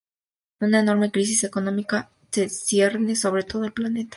Read more enormous, huge Opposite of exiguo, mínimo, pequeño Frequency B1 Hyphenated as e‧nor‧me Pronounced as (IPA) /eˈnoɾme/ Etymology Borrowed from Latin enormis In summary Borrowed from Latin enormis.